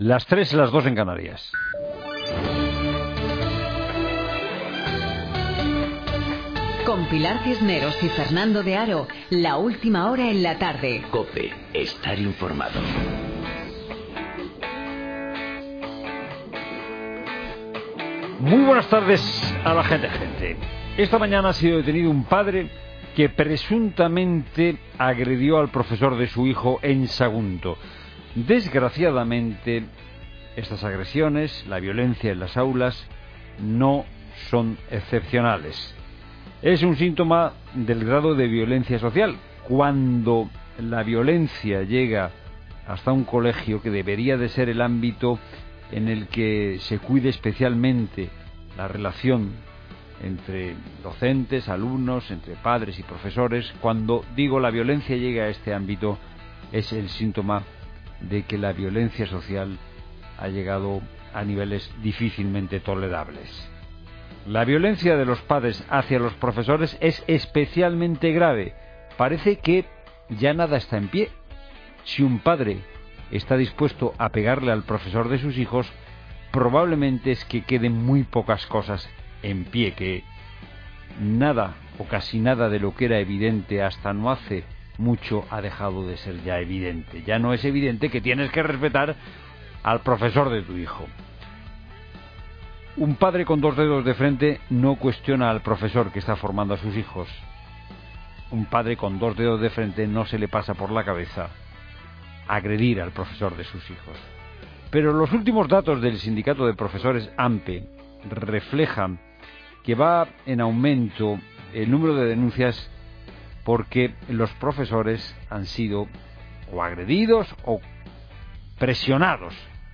Además, una empresa que probó la semana laboral de 4 días, la ha implantado definitivamente. 'La Tarde', un programa presentado por Pilar Cisneros y Fernando de Haro, es un magazine de tarde que se emite en COPE , de lunes a viernes, de 15 a 19 horas.